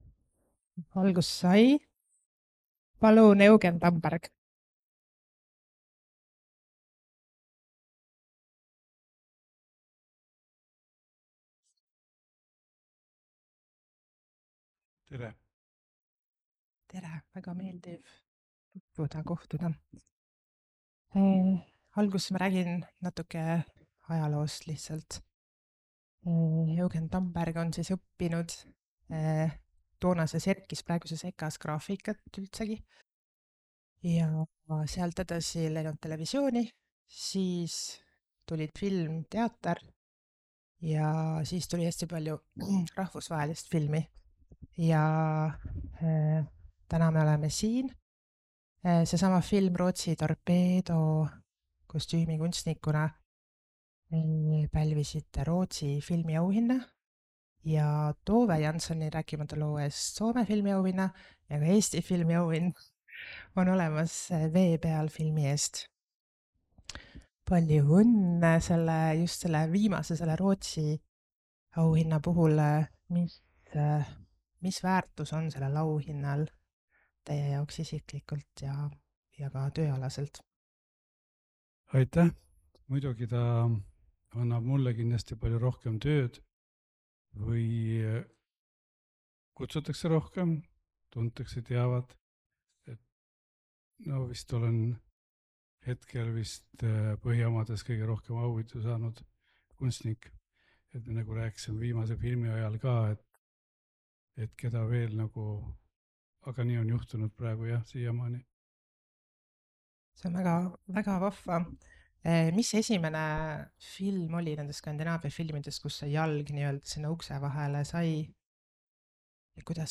NB! Vestluse salvestus sisaldab sisurikkujaid.